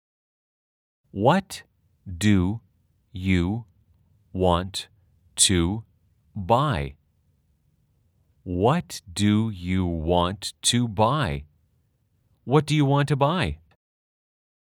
/ 왓 두유 원투 / 바아이 /
아주 천천히-천천히-빠르게 3회 반복 연습하세요.